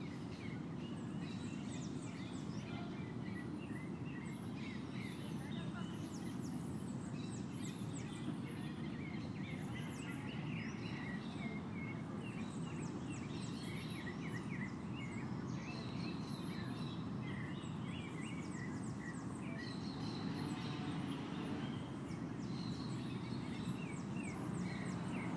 Butantan-sons matinais